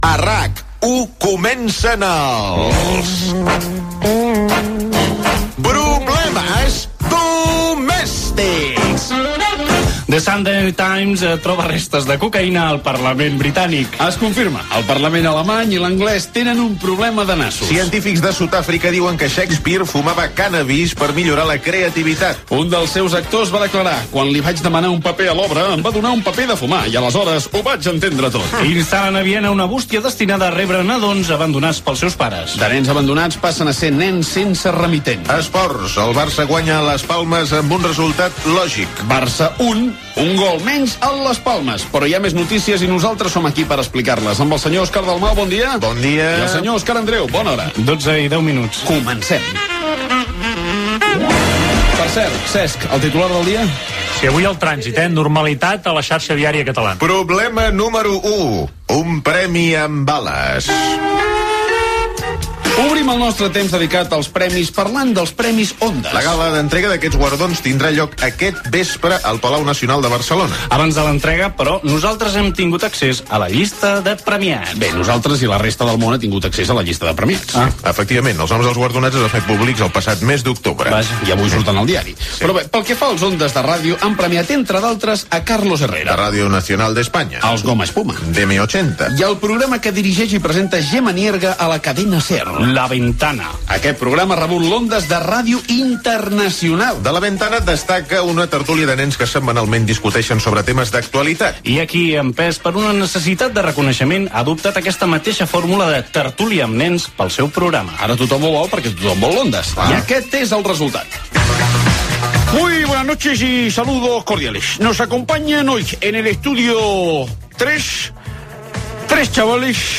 Careta del programa
Gènere radiofònic Entreteniment